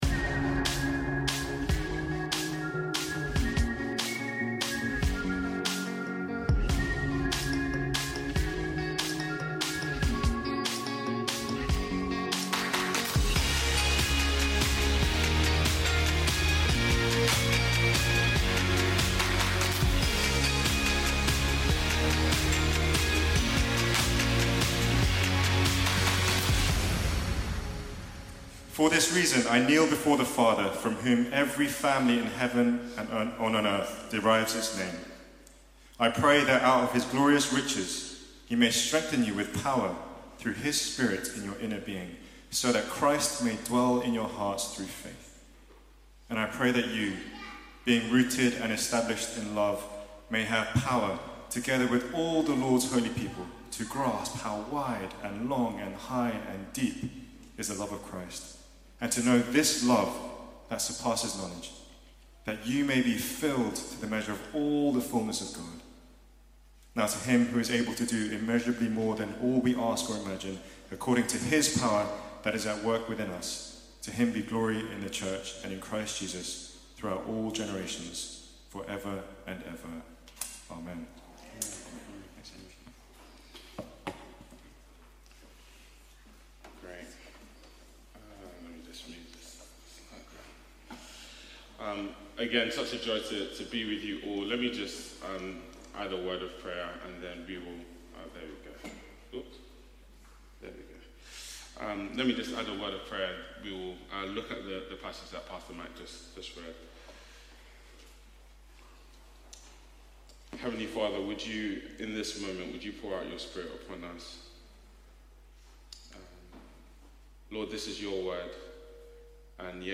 Sunday Sermons - Reality Church London podcast